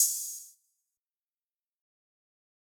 TM88 - OPEN HAT (2).wav